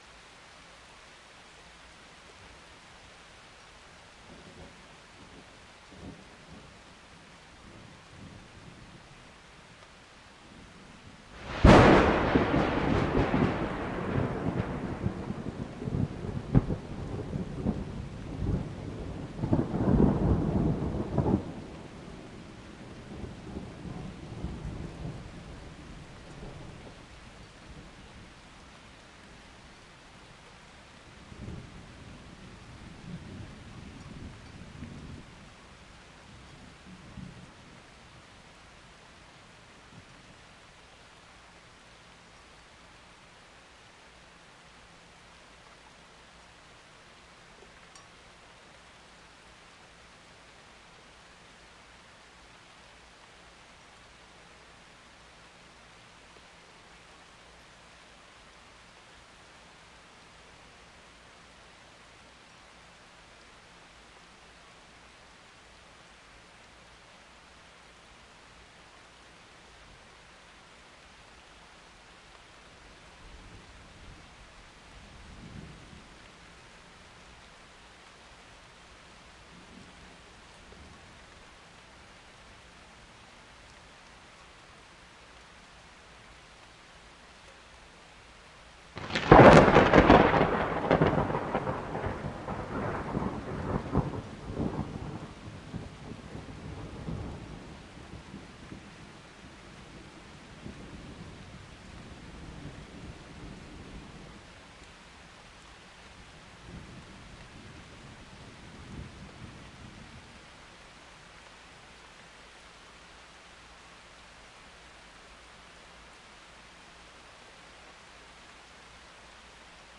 Field Recordings » Thunder Storm
描述：A MidSide Recording of some Thunder going off, Mic (zoom h2n) pointed out of my window
标签： wind loud thunderstorm strike storm rolling weather rumble shower lightning rain thunderstorm flash rollingthunder thunder nature fieldrecording raining
声道立体声